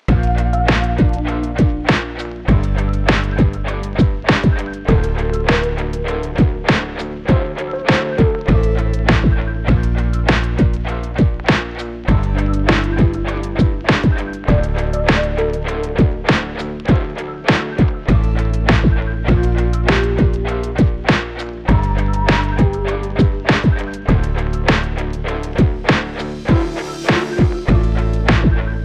Music - Song Key
D Minor
Music - Percussion
Rap Club
Music - Bass
Music - Power Chords